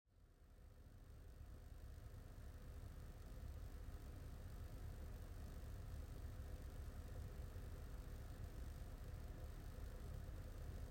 BeQuiet DARK POWER 12 850W ATX 80PLUS® Titanium Lüfter Defekt ? (Audio Aufnahme)
2. Netzteil